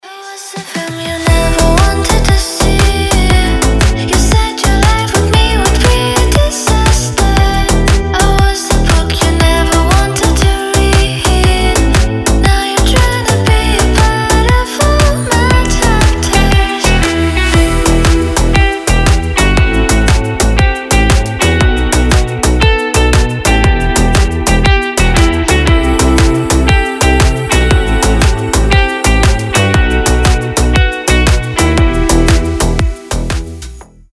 • Качество: 320, Stereo
deep house
женский голос